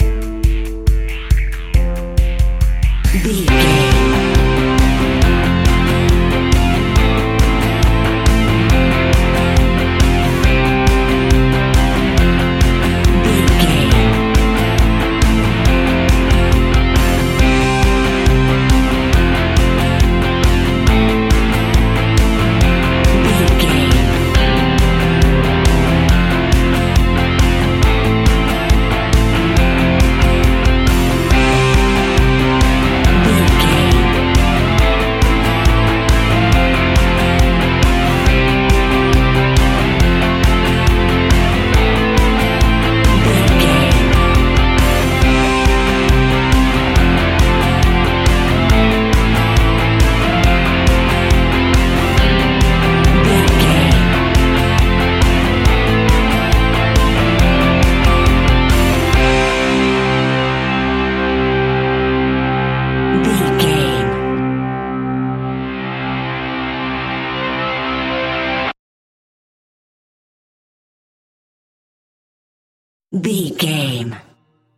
Ionian/Major
energetic
driving
heavy
aggressive
electric guitar
bass guitar
drums
indie pop
uplifting
piano
organ